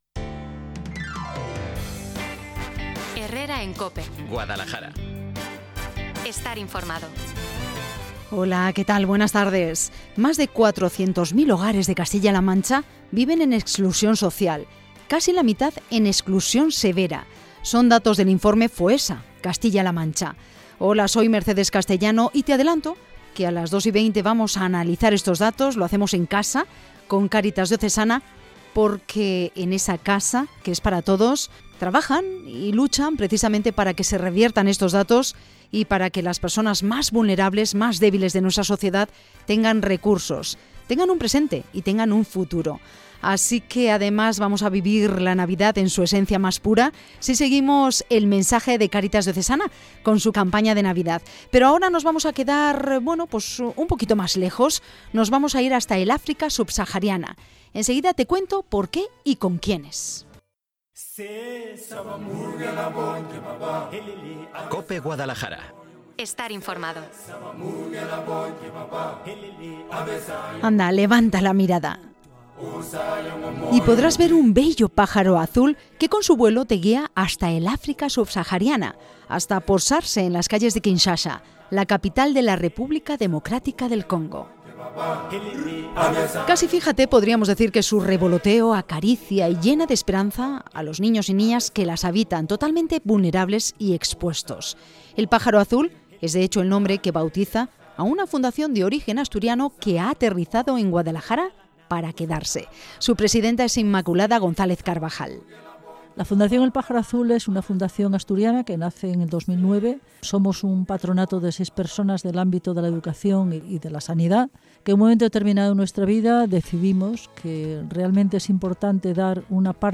Enlace de la entrevista: